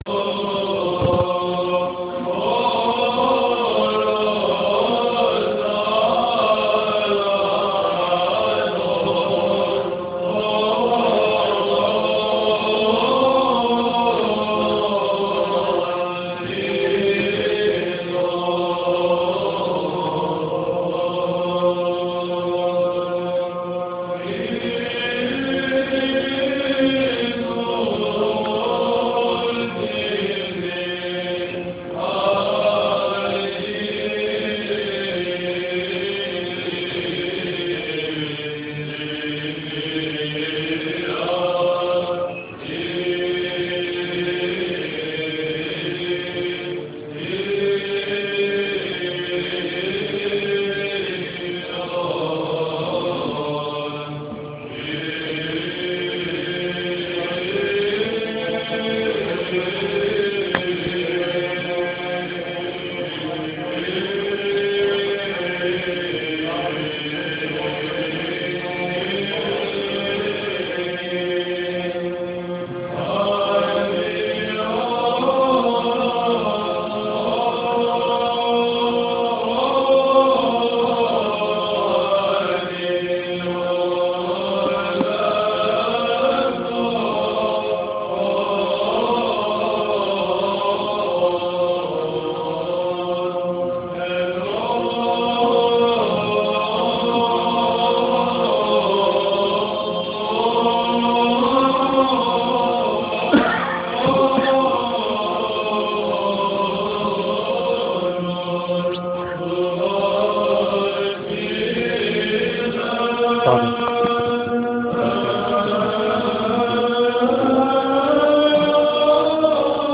HOCNA Synaxis 2011
St. Mark's Choir - Of Prophets Old
(sample of the St. Mark’s Choir chanting “Of Prophets Old” – chanted during the vesting of the Bishop – QuickTime required to play)